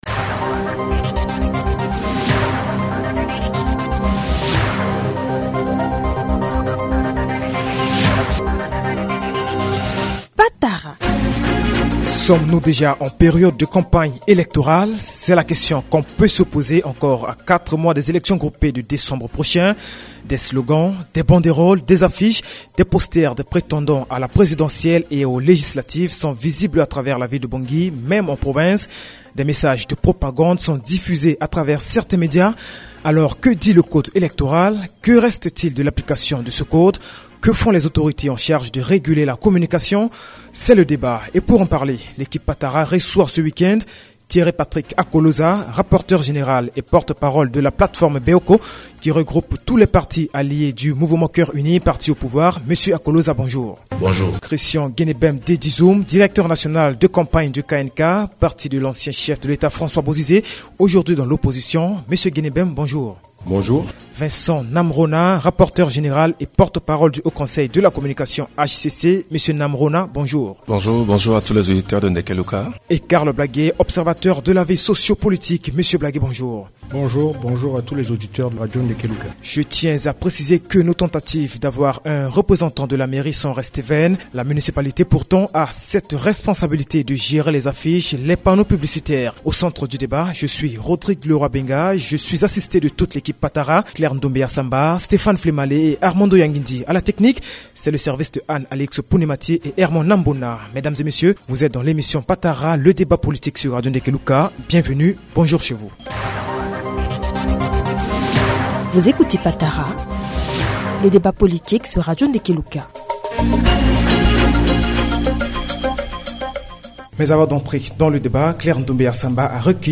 Que font les autorités en charge de réguler la communication ? C’est le débat.